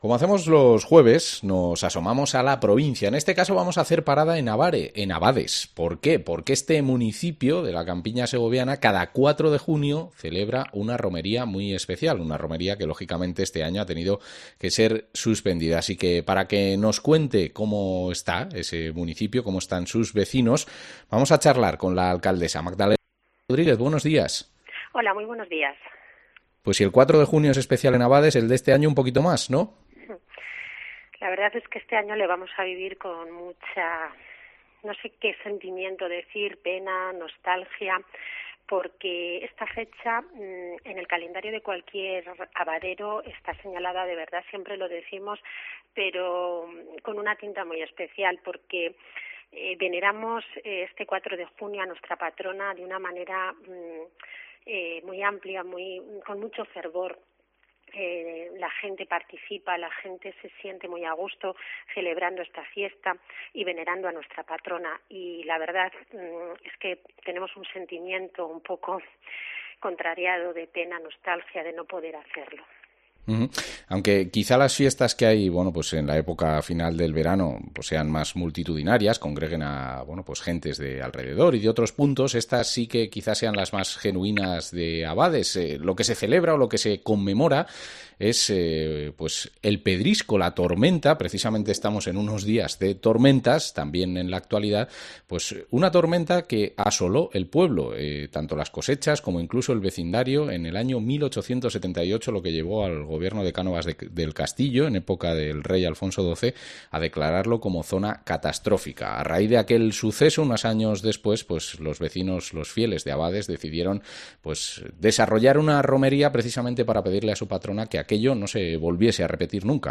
Entrevista a la alcaldesa de Abades, Magdalena Rodríguez